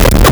Player_Glitch [80].wav